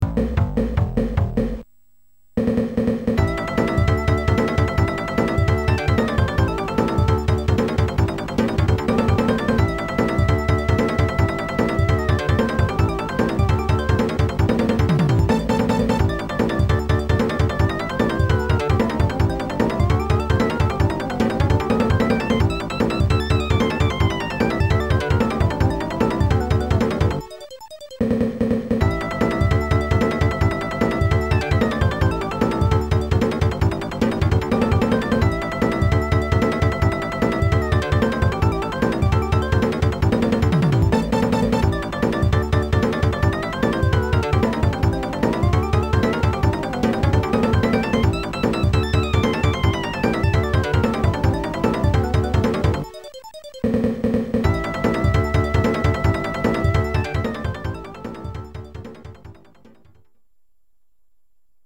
Did I mention that the music is equally as fast: